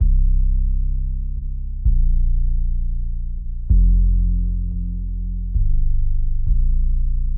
陷阱或Hip Hop低音130 BPM
Tag: 130 bpm Trap Loops Bass Synth Loops 1.24 MB wav Key : Unknown FL Studio